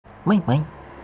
puke_2-online-audio-converter.mp3